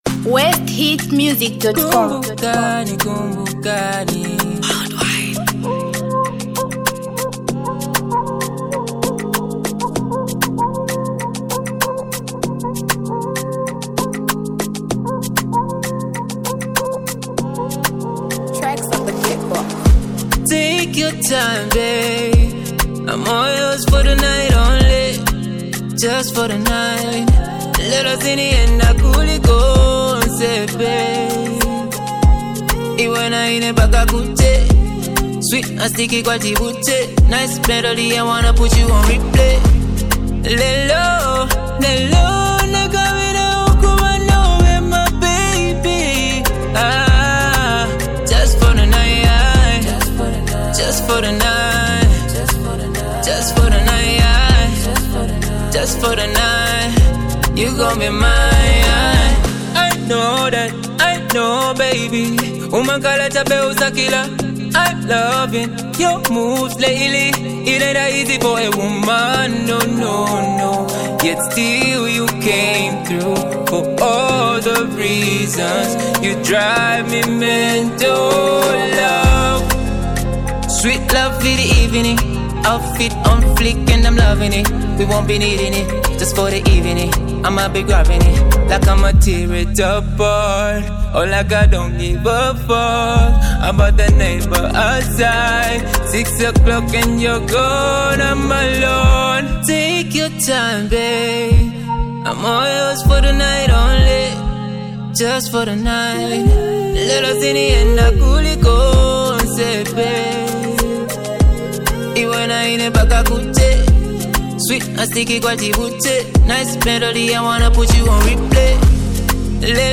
Zambia Music
featuring the talented female artist